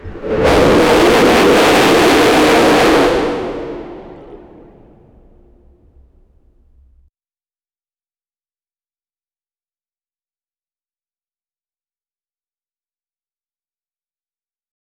Free AI Sound Effect Generator